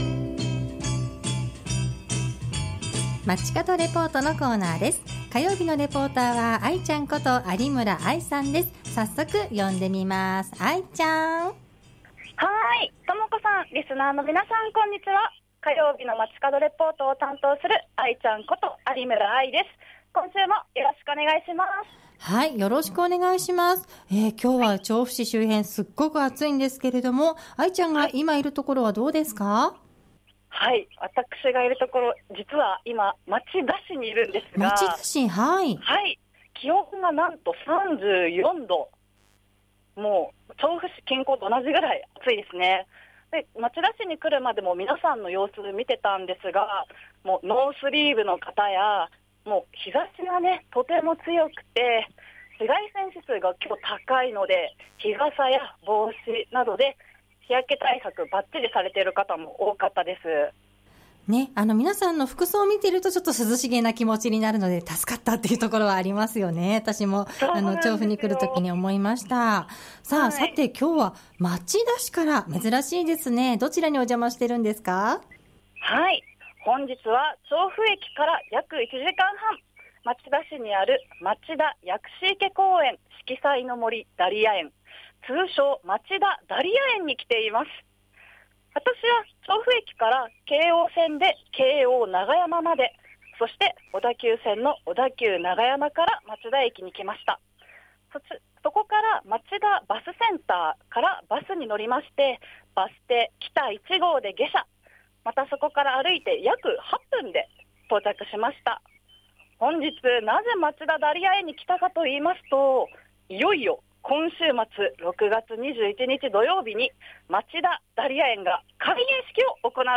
本日は調布を飛び出しまして、町田市にある「町田薬師池公園 四季彩の杜 ダリア園」、通称「町田ダリア園」からお届けしました！